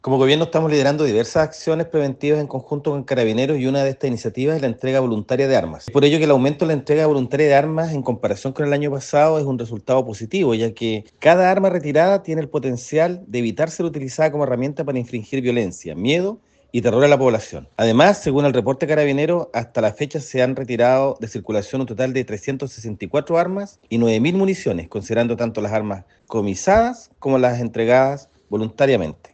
Consultado al delegado Presidencial Regional de Los Ríos, Jorge Alvial, mencionó que la cantidad de armas entregadas voluntariamente ha aumentado.